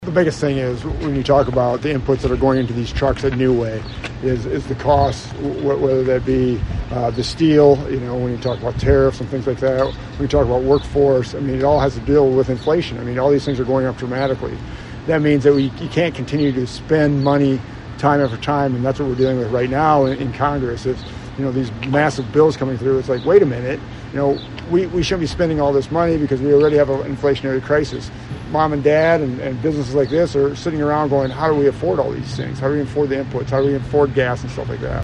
Feenstra and Cotton made their comments at Scranton Manufacturing following their tour.